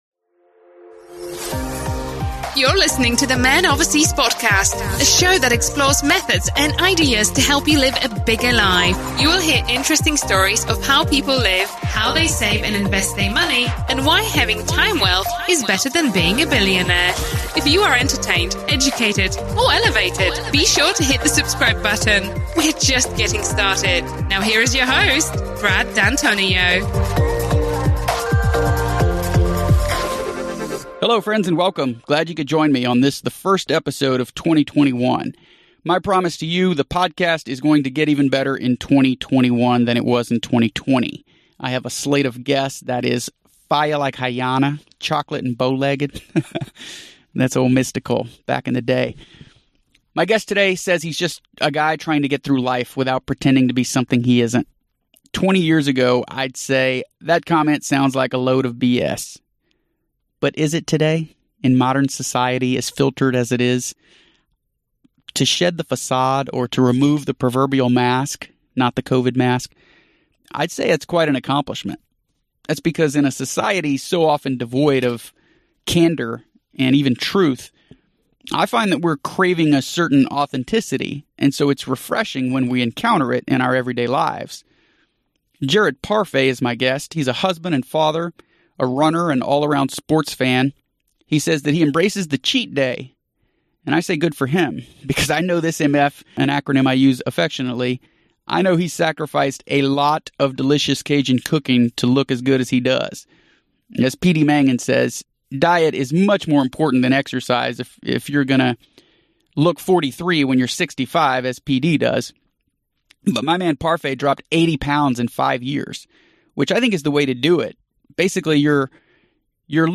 This conversation was a blast.